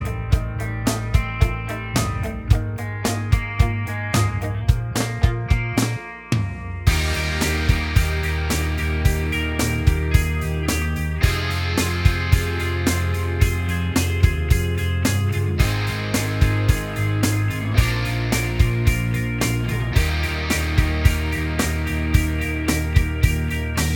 Minus Main Guitar Pop (2000s) 3:55 Buy £1.50